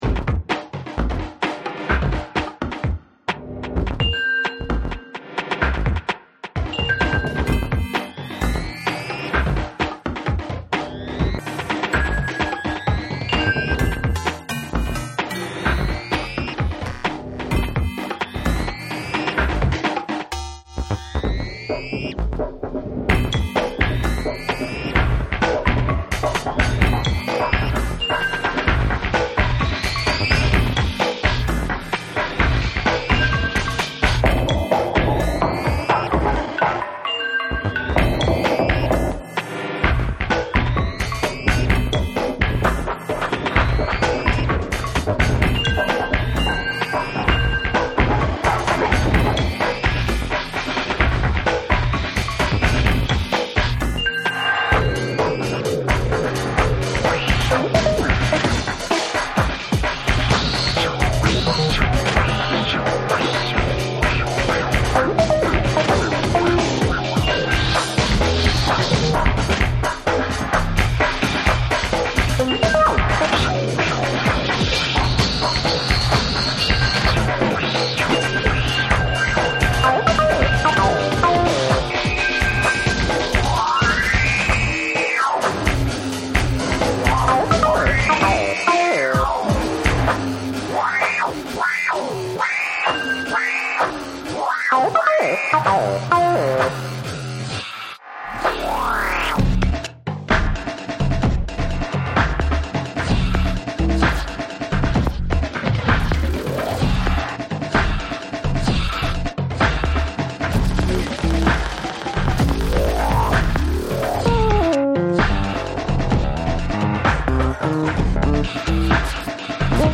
hey, that was a great tune! very playful. it ends rather